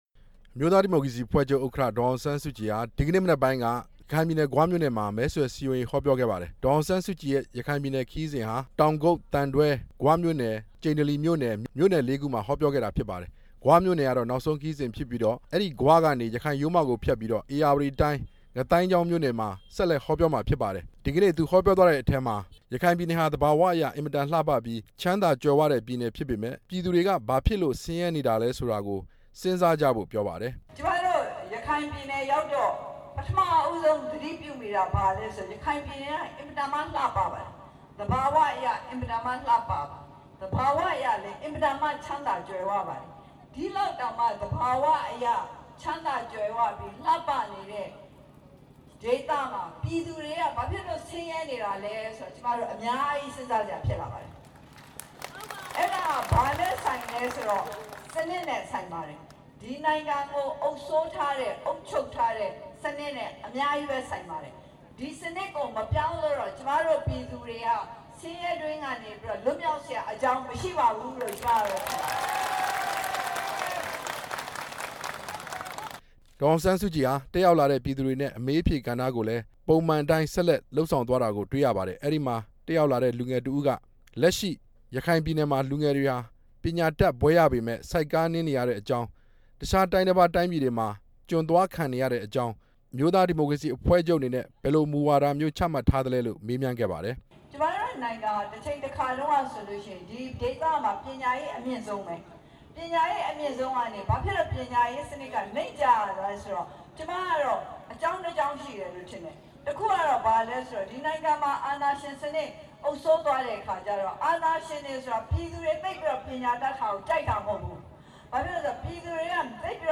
ဂွမြို့မှာ ပြောကြားချက်
အမျိုးသားဒီမိုကရေစီအဖွဲ့ချုပ် ဥက္ကဌ ဒေါ်အောင်ဆန်းစုကြည်ဟာ ရခိုင်ပြည်နယ် ဂွမြို့မှာ ဒီကနေ့ မနက်က ရွေးကောက်ပွဲအောင်နိုင်ရေး မဲဆွယ်စည်းရုံးဟောပြောခဲ့ပါတယ်။ ဂွမြို့ဟောပြောပွဲဟာ ရခိုင်ပြည်နယ်ခရီးစဉ်ရဲ့ နောက်ဆုံးဖြစ်ပါတယ်။